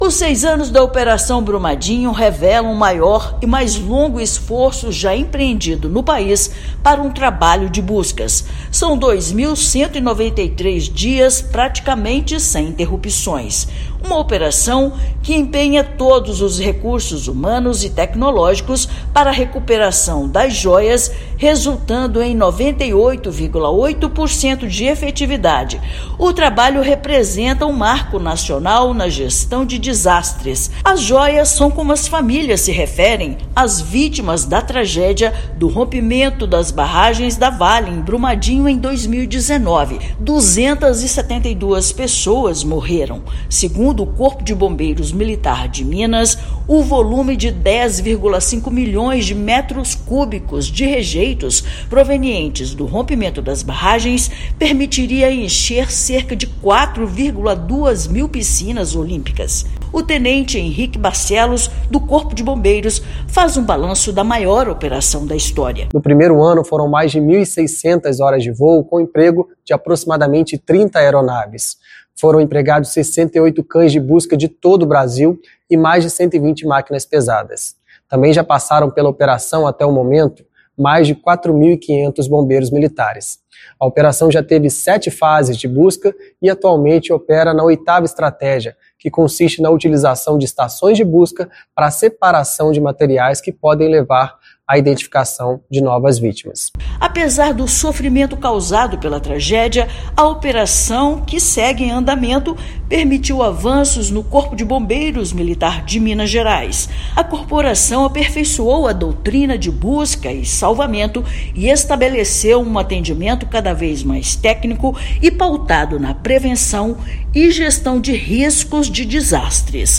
Rompimento das barragens de rejeitos de minério da Vale no município completa seis anos neste sábado (25/1) e deixa legado à corporação de atendimento cada vez mais técnico e pautado na prevenção de risco de desastres. Ouça matéria de rádio.